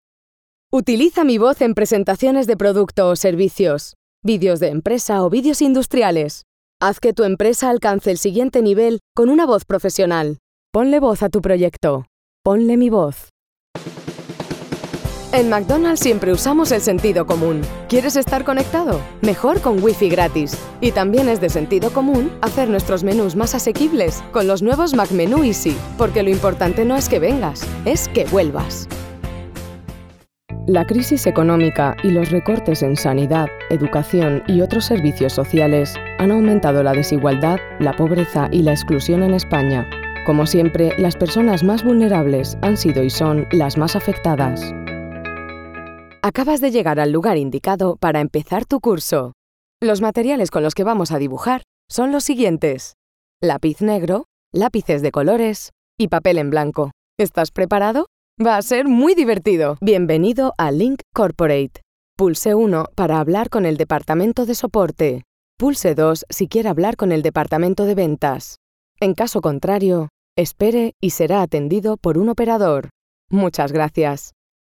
Voix off narration en espagnole
Je suis a voix off feminine espagnole pour:
Narratrice